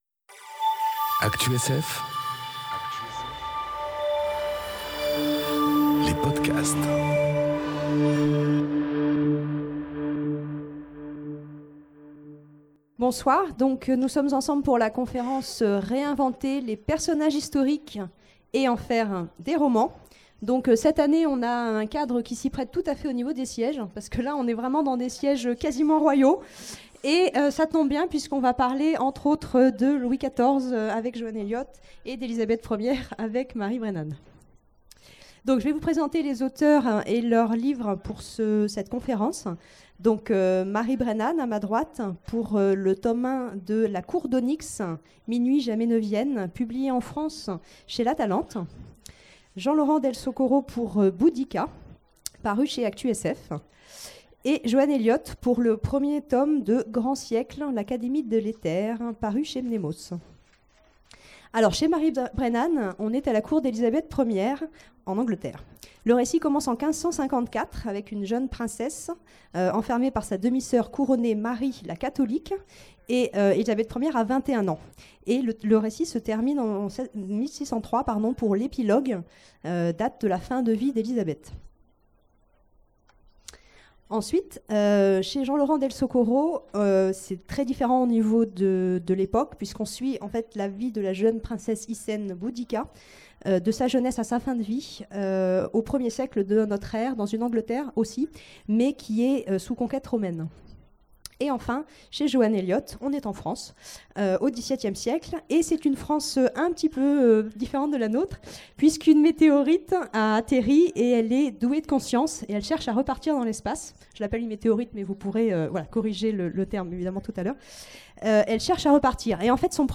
Conférence Réinventer les personnages historiques... Et en faire des romans enregistrée aux Imaginales 2018